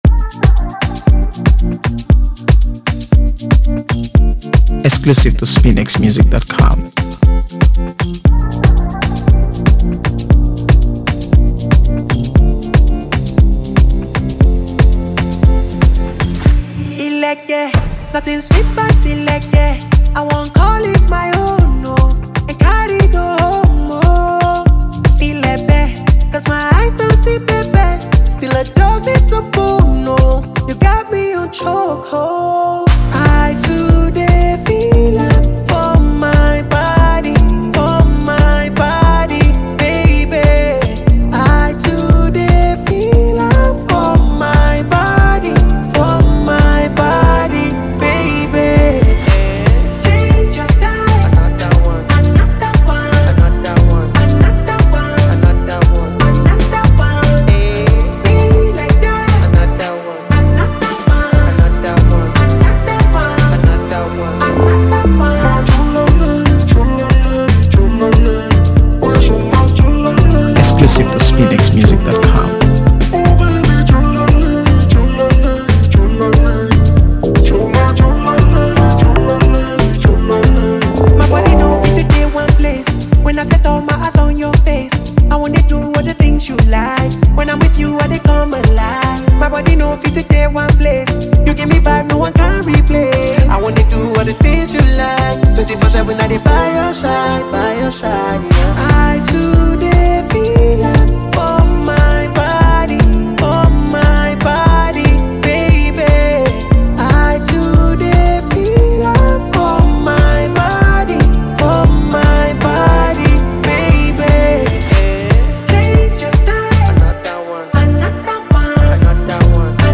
AfroBeats | AfroBeats songs
Nigerian singer and composer
carrying forward an unforgettable melody and vibrant energy.